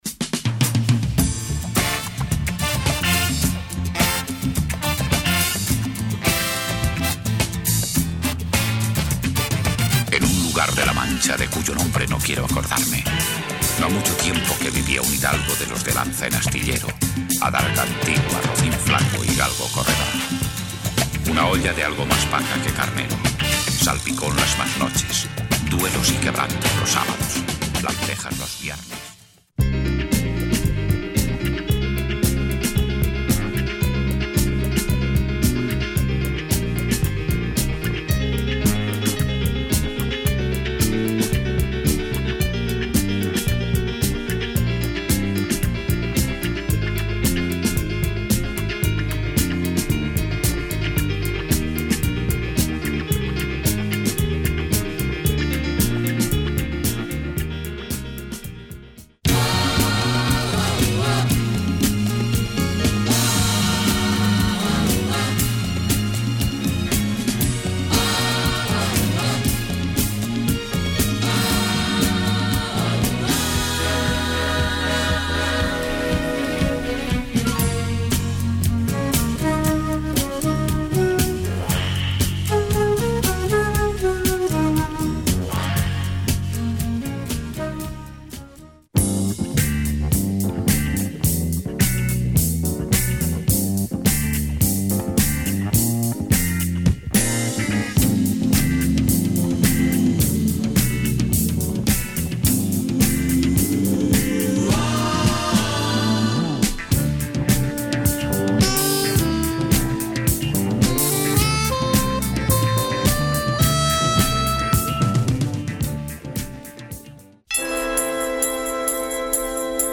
Classic Spanish funk, always very popular.